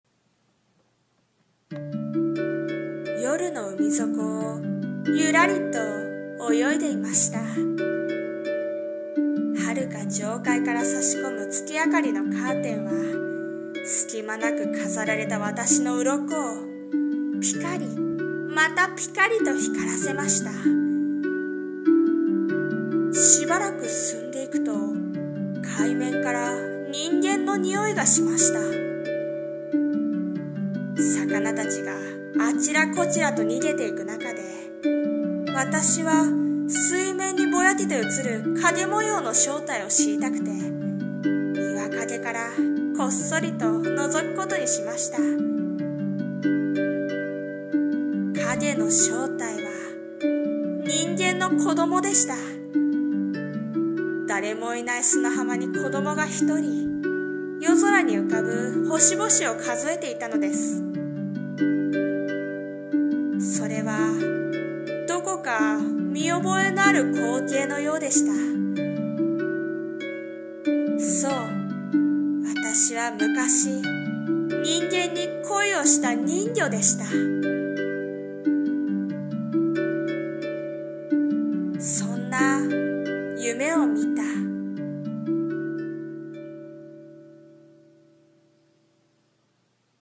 さんの投稿した曲一覧 を表示 Original台本『Mermaid Syndrome』BGM: 海 / 朗読